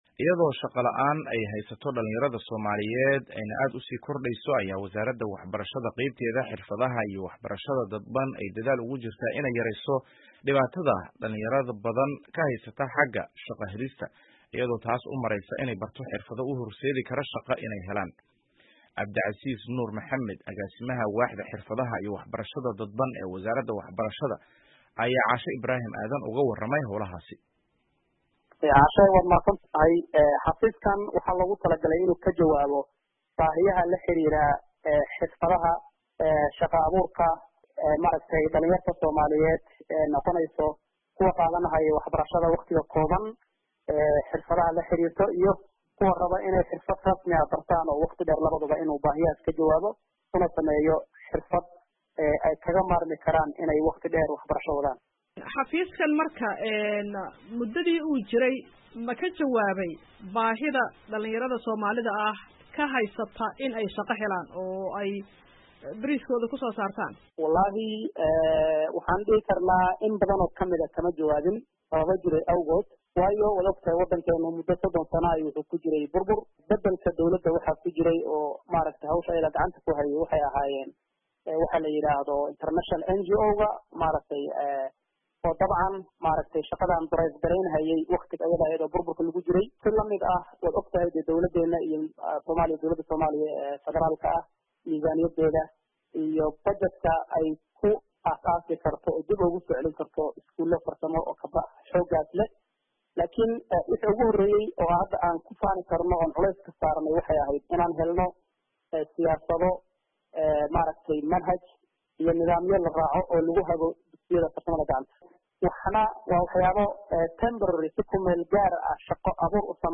Wareysi: Dadaallada shaqo abuurka dhallinyarada ee Wasaaradda Waxbarashada